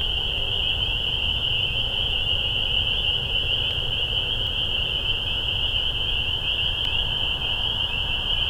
peeps.wav